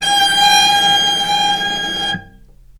vc_sp-G#5-ff.AIF